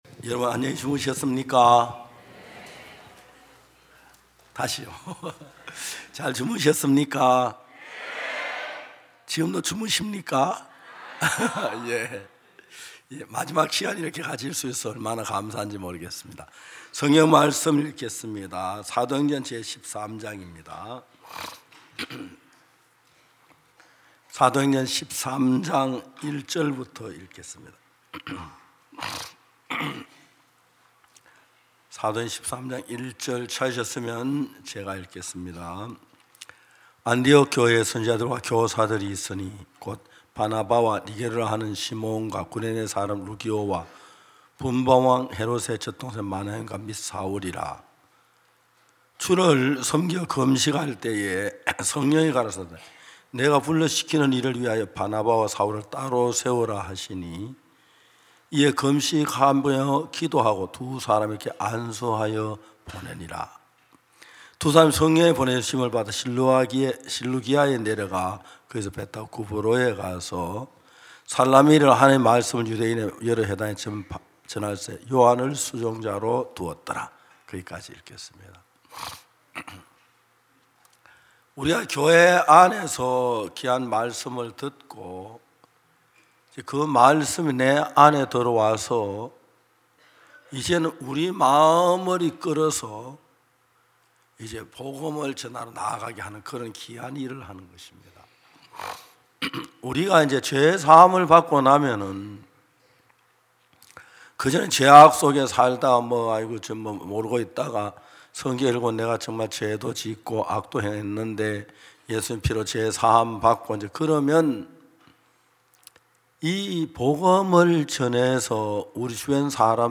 매해 여름과 겨울, 일 년에 두 차례씩 열리는 기쁜소식선교회 캠프는 아직 죄 속에서 고통 받는 사람들에게는 구원의 말씀을, 일상에 지치고 마음이 무뎌진 형제자매들에게는 기쁨과 평안을 전하고 있습니다. 매년 굿뉴스티비를 통해 생중계 됐던 기쁜소식 선교회 캠프의 설교 말씀을 들어보세요.